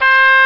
Dulcian Hi Sound Effect
dulcian-hi.mp3